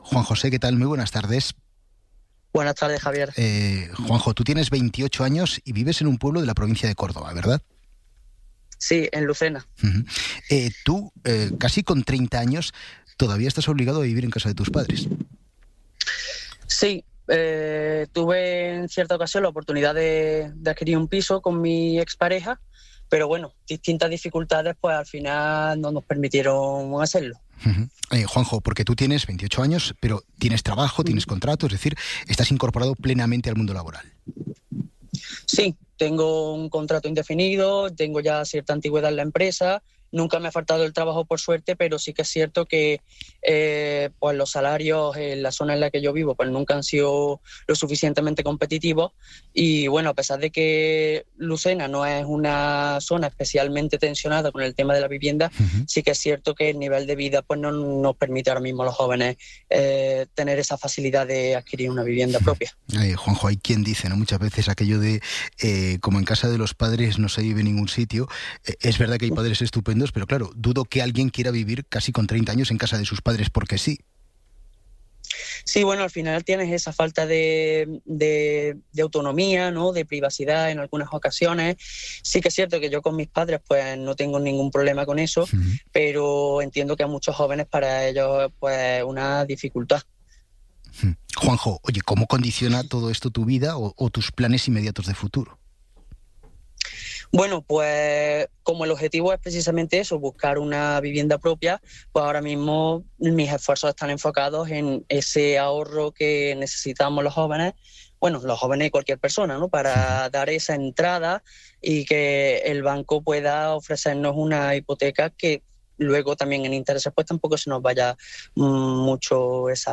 'Hora 14' es el informativo líder del mediodía.